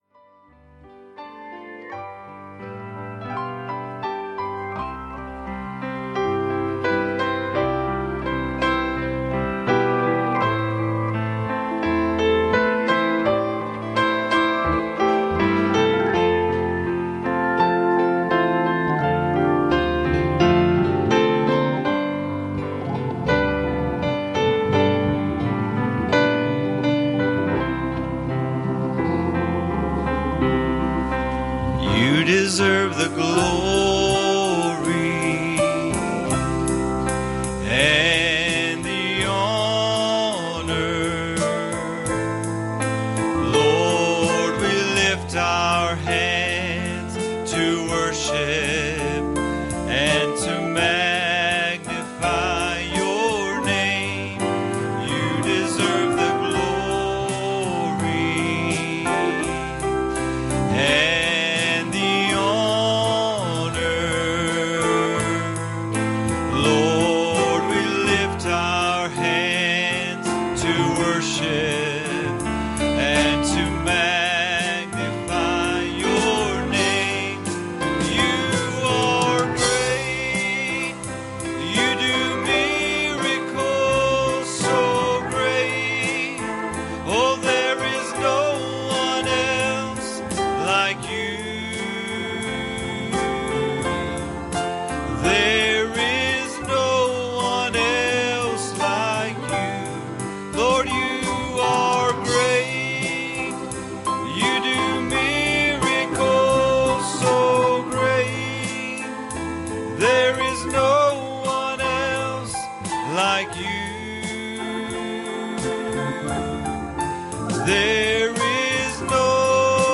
2 Thessalonians 2:1 Service Type: Wednesday Evening « Yield Pt36